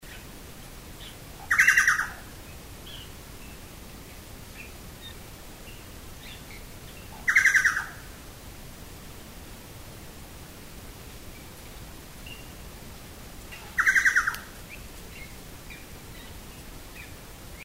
Scelorchilus rubecula
Nome em Inglês: Chucao Tapaculo
Fase da vida: Adulto
País: Chile
Localidade ou área protegida: Valdivia
Condição: Selvagem
Certeza: Gravado Vocal
Scelorchilus_rubecula_valdivia_2_iphone.mp3